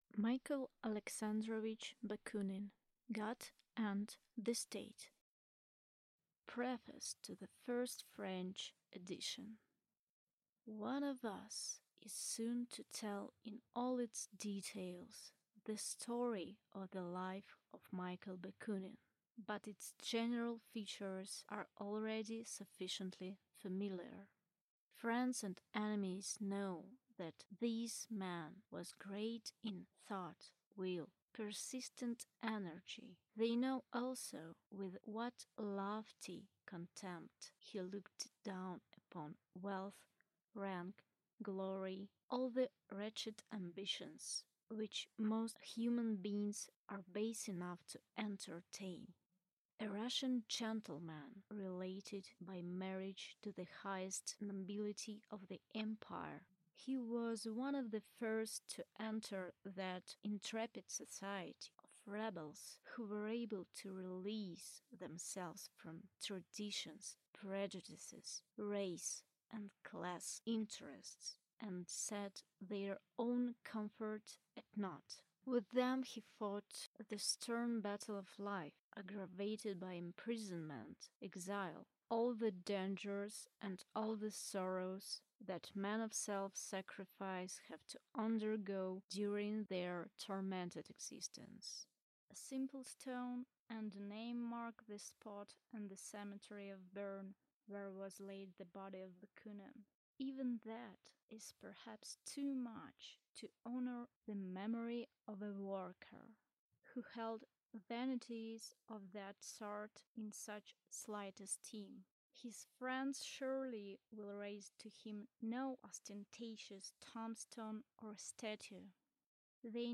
Аудиокнига God and the State | Библиотека аудиокниг